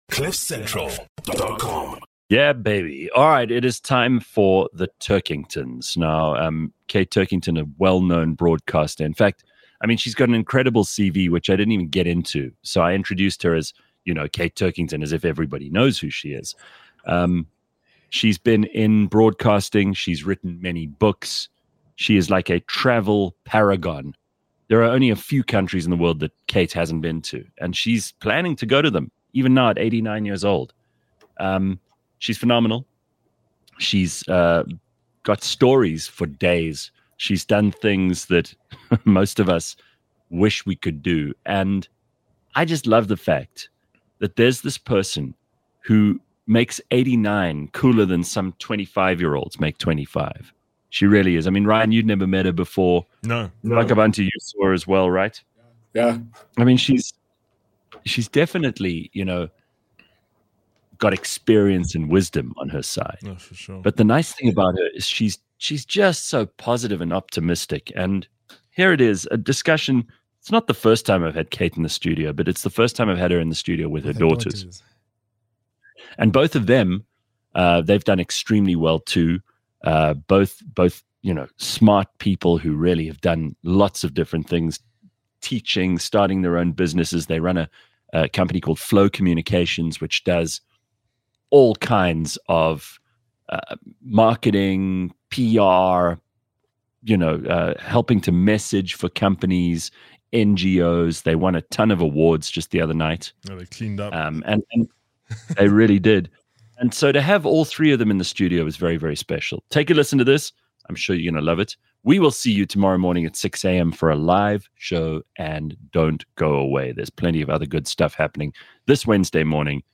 A live podcast show, that’s like a morning radio show, just much better.
Clever, funny, outrageous and sometimes very silly.